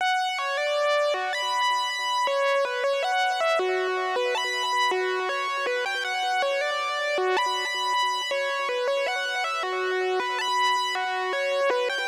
PBS (Retro LD 159Bpm).wav